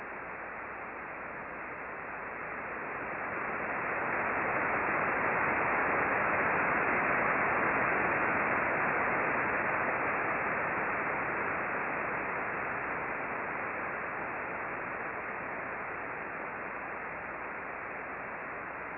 Click here for a recording of receiver audio between 1600:43 and 1601:06, corresponding to the trace above